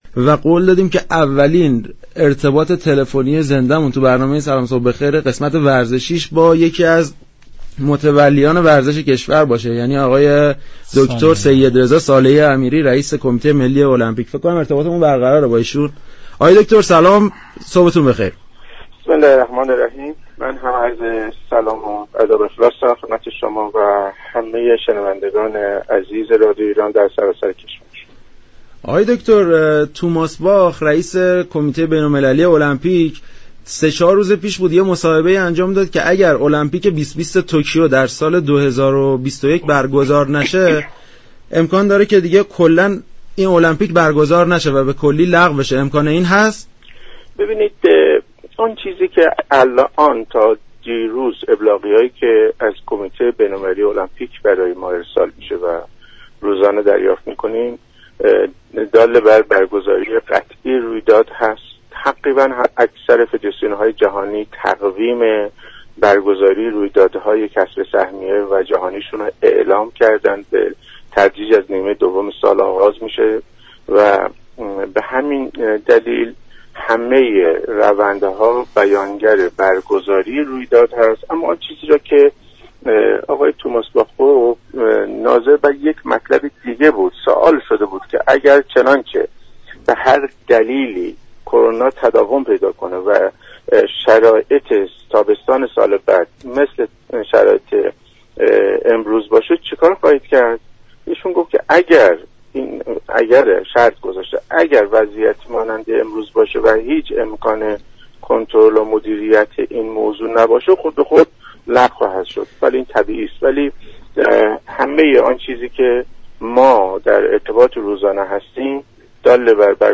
به گزارش شبكه رادیویی ایران، «سیدرضا صالحی امیری» رئیس كمیته ملی المپیك جمهوری اسلامی ایران، در برنامه «سلام صبح بخیر» درباره احتمال لغو كامل بازی های المپیك، گفت: طبق ابلاغیه های ارسالی از كمیته بین المللی المپیك، برگزاری بازی های المپیك همچنان به قوت خود باقی است و بیشتر فدراسیون های جهانی تقویم برپایی رویدادهای كسب سهمیه شان را اعلام كردند.